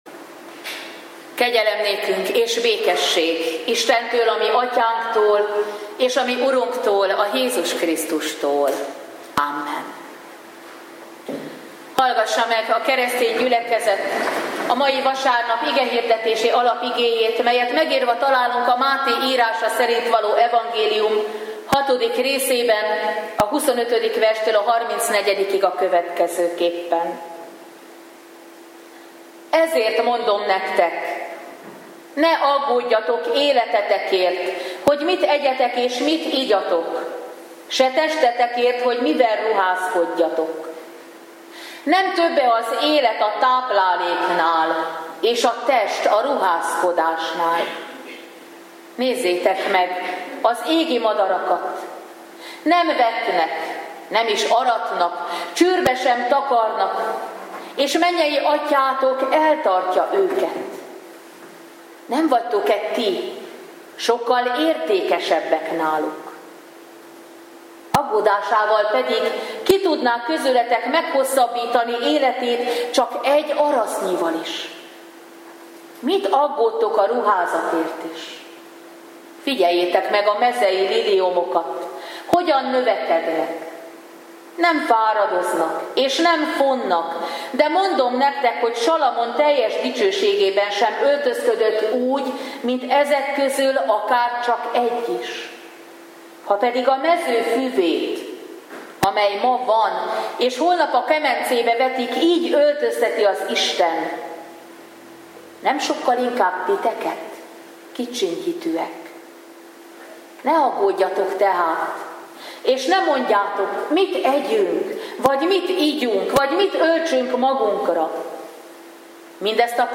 Szentháromság ünnepe után 15. vasárnap - Minden gondotokat őreá vessétek, mert neki gondja van rátok.
Igehirdetések